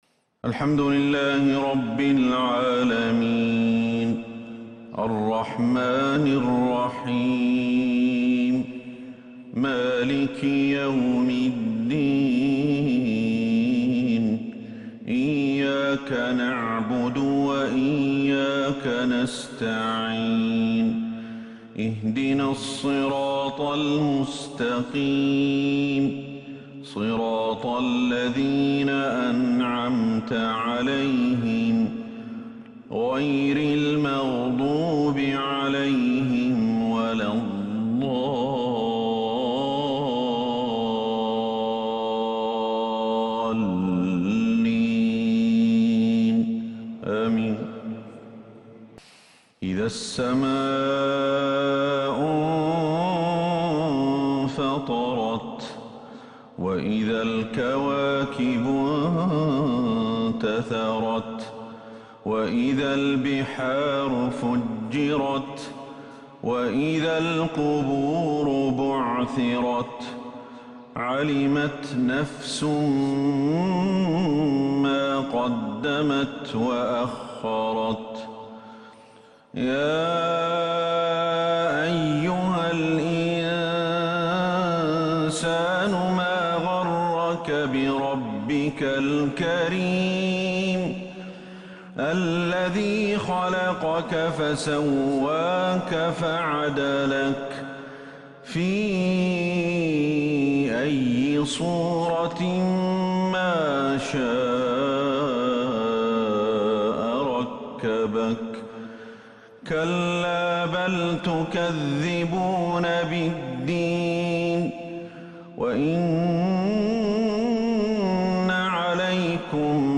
مغرب 7-5-1442هـ سورتي {الانفطار}{الزلزلة} > 1442 هـ > الفروض - تلاوات الشيخ أحمد الحذيفي